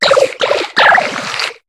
Cri de Colimucus dans Pokémon HOME.